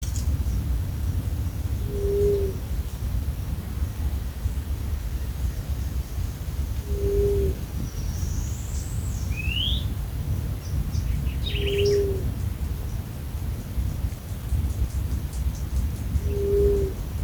Yerutí Colorada (Leptotila rufaxilla)
Nombre en inglés: Grey-fronted Dove
Localización detallada: PP Teyú Cuaré
Condición: Silvestre
Certeza: Observada, Vocalización Grabada